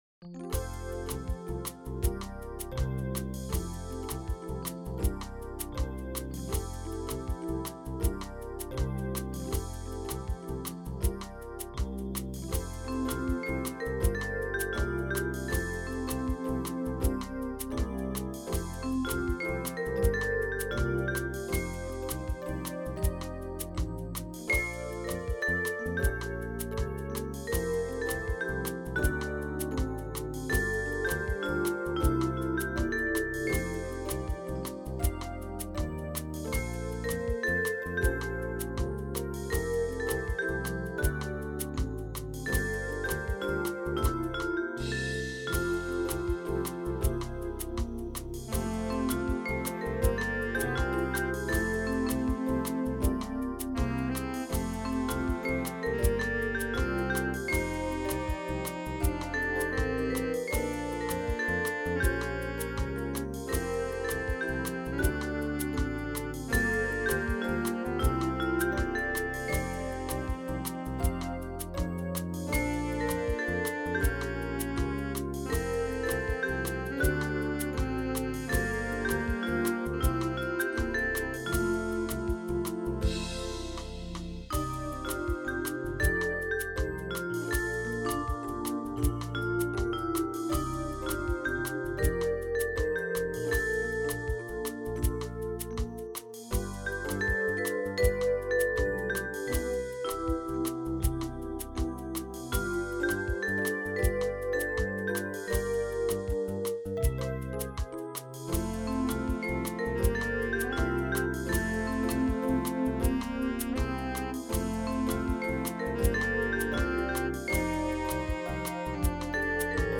Vibraphone in the lead